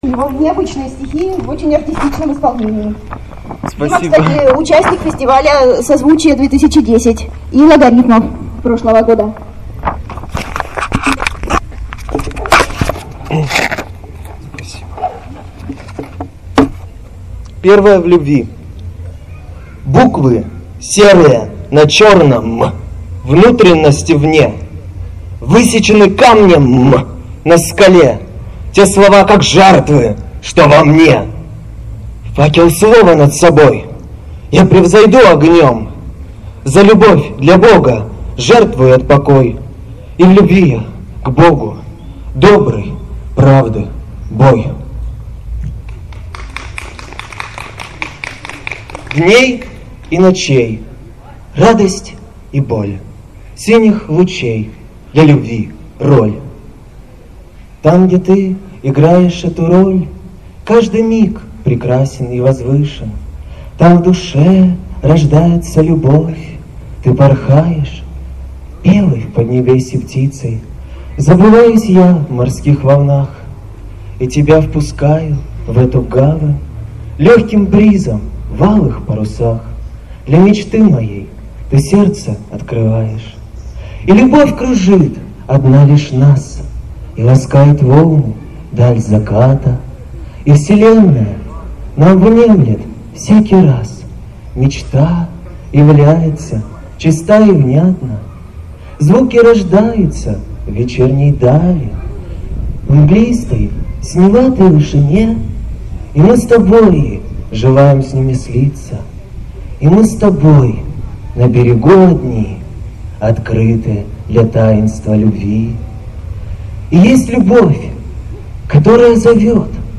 Ньюэйдж
Концерт Чтения